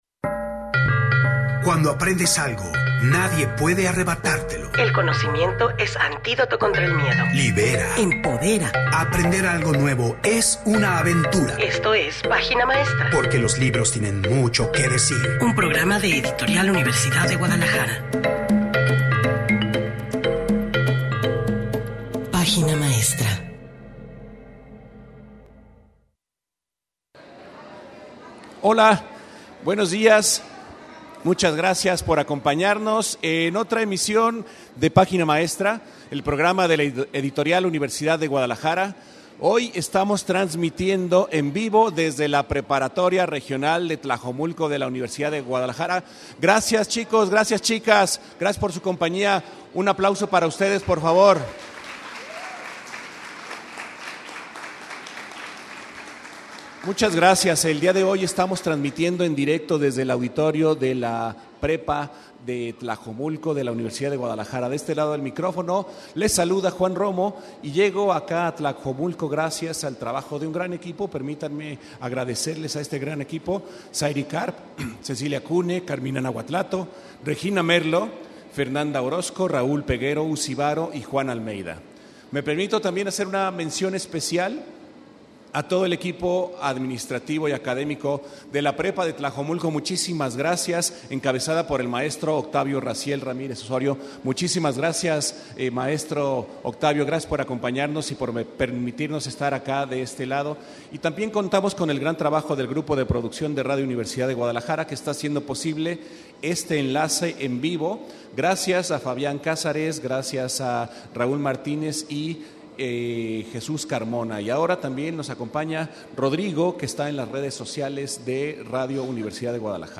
¡Estamos en vivo!